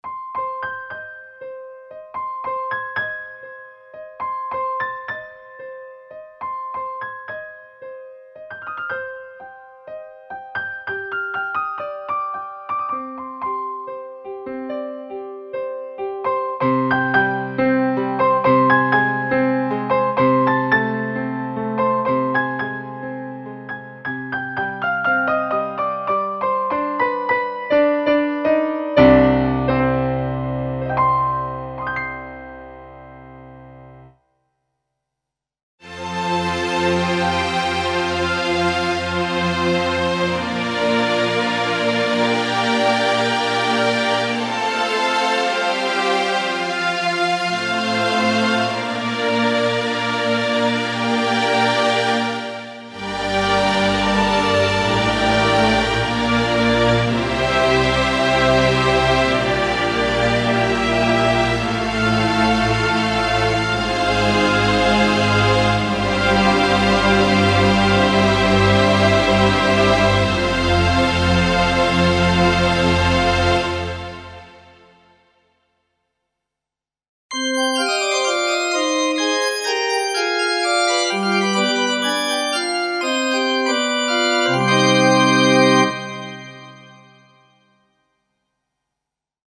Collection of classical instruments (acoustic pianos, guitars, pipe organs, strings, etc.).
Info: All original K:Works sound programs use internal Kurzweil K2600 ROM samples exclusively, there are no external samples used.